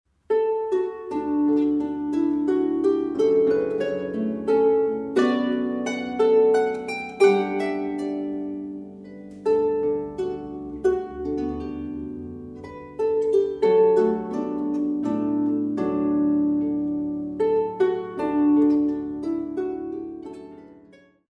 O-Carolan_Harfe.mp3